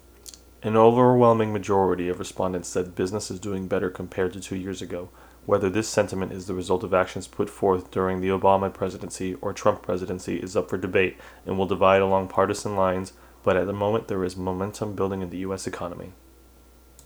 I had to cut the gain to about 10 o’clock as the levels were hitting red.
Here is a reading of a news article.
The last test had a lot of expression in it. Big differences between the loudest and softest words.